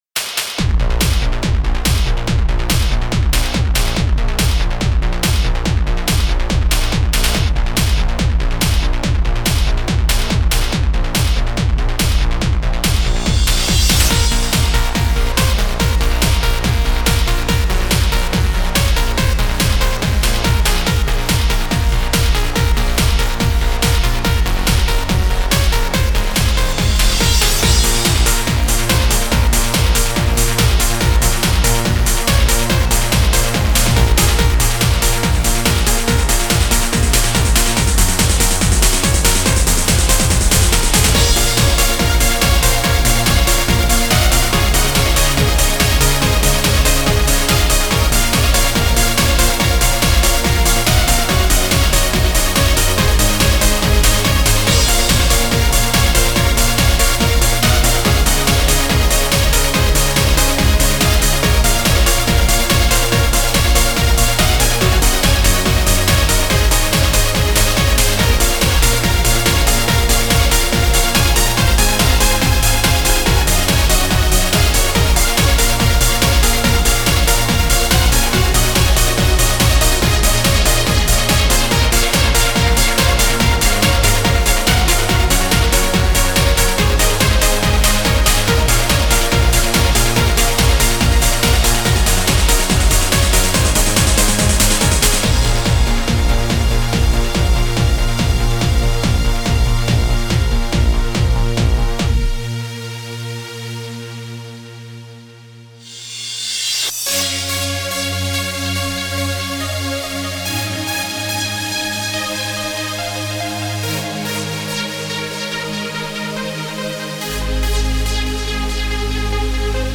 Главная » Файлы » Trance , Disco, Club , D&J